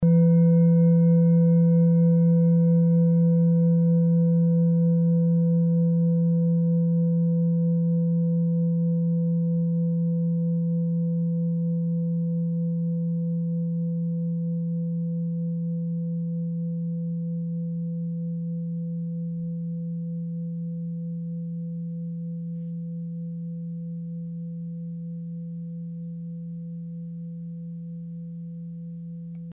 Klangschale TIBET Nr.28
Sie ist neu und ist gezielt nach altem 7-Metalle-Rezept in Handarbeit gezogen und gehämmert worden..
(Ermittelt mit dem Filzklöppel)
klangschale-tibet-28.mp3